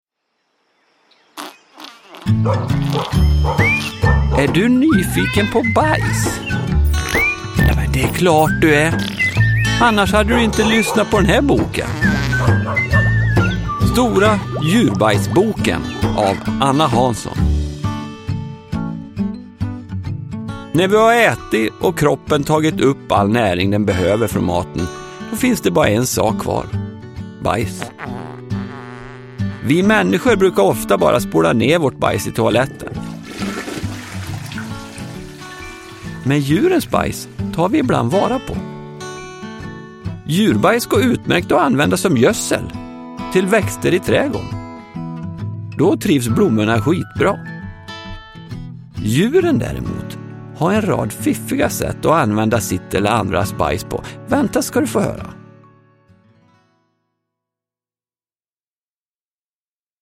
Stora djurbajsboken – Ljudbok – Laddas ner